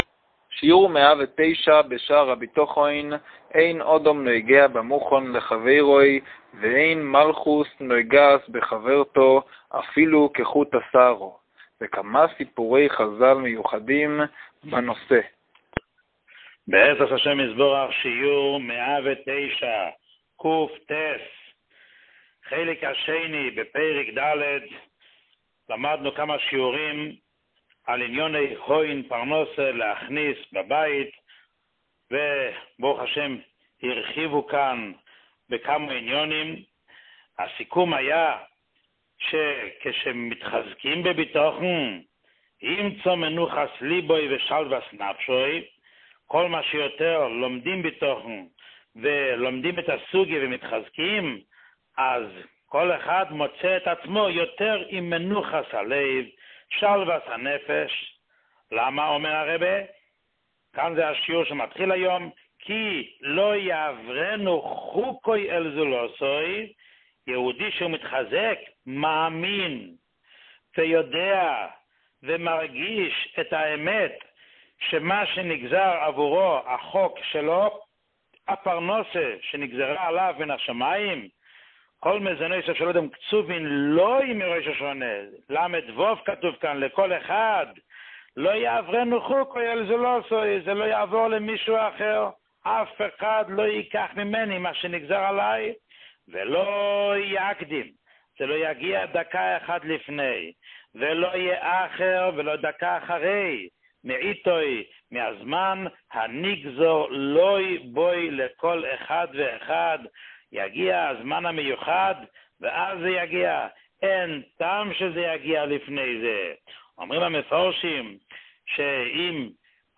שיעור 109